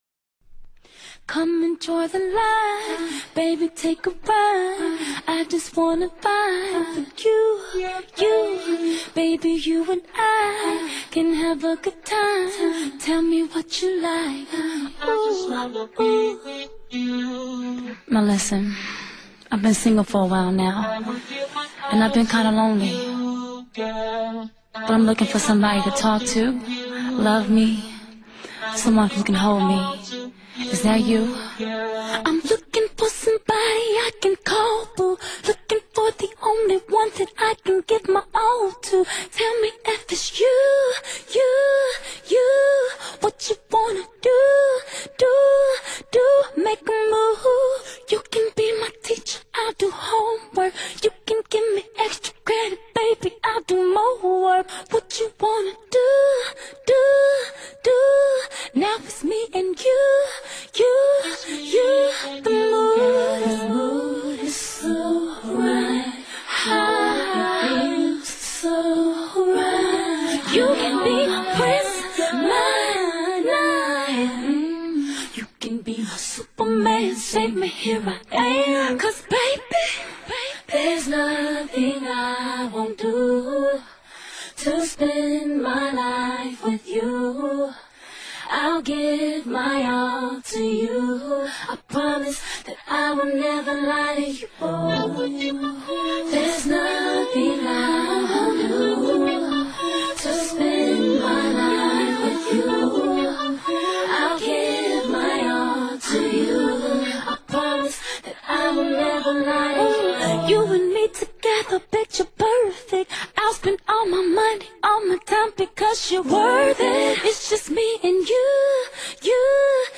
Главная » Файлы » Акапеллы » Скачать Зарубежные акапеллы